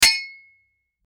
Metal Clank 04
Metal_clank_04.mp3